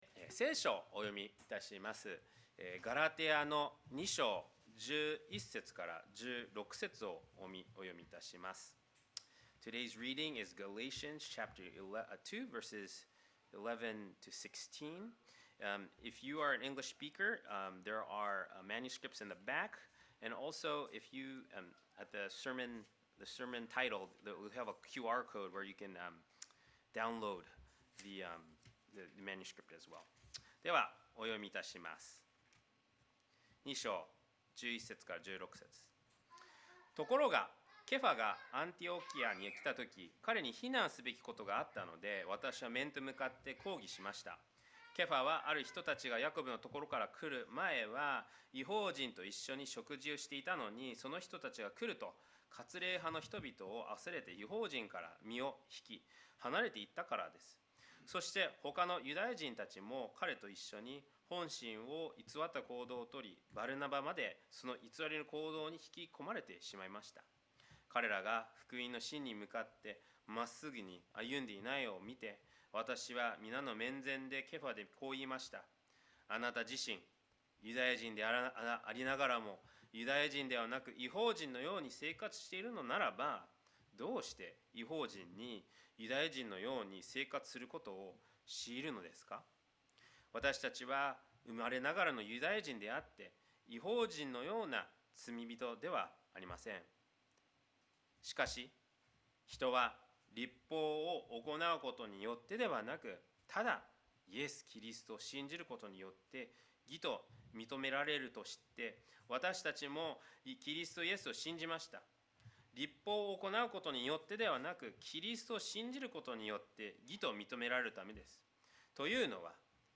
2025年9月14日礼拝 説教 「義と認められる恵み」 – 海浜幕張めぐみ教会 – Kaihin Makuhari Grace Church